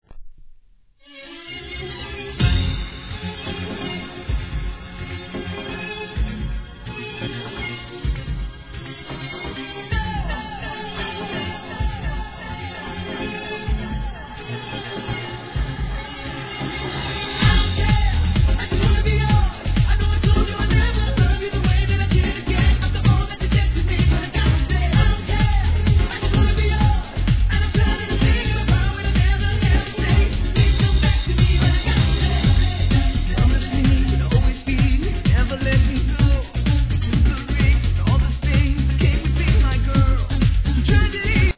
HIP HOP/R&B
■REGGAETON